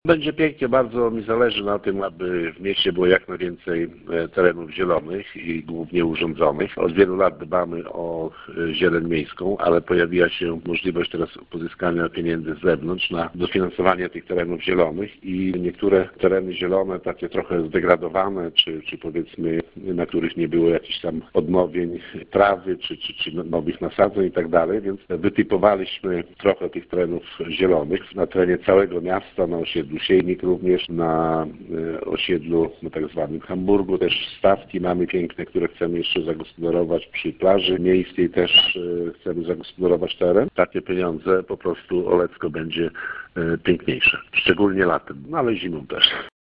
-Skorzysta na tym bez wątpienia wizerunek miasta, które będzie jeszcze bardziej atrakcyjne dla turystów, mówi Wacław Olszewski, burmistrz Olecka.